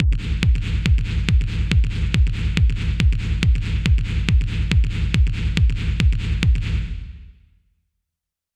I’ve settled on a predelay of 104 ms, but this will vary depending on the tempo of your track. I’ve also lowered the decay parameter to 55 to allow the reverb tail to have mostly dropped off by the time the next kick hits.